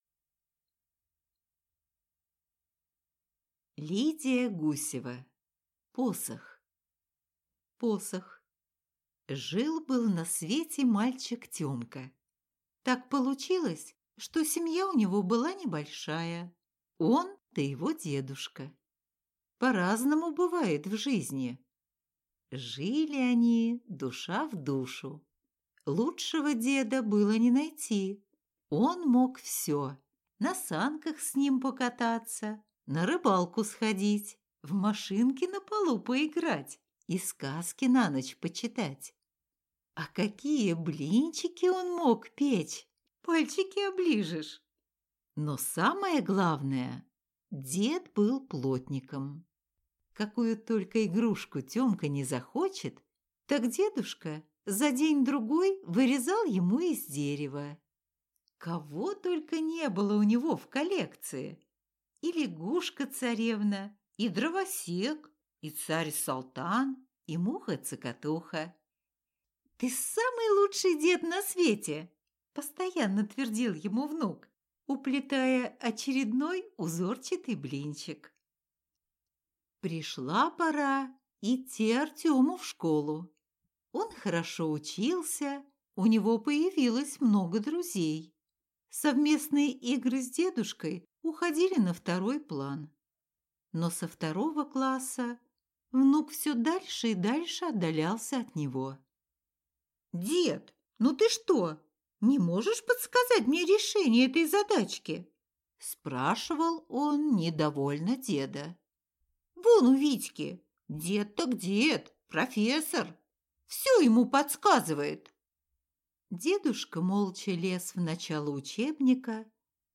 Аудиокнига Посох | Библиотека аудиокниг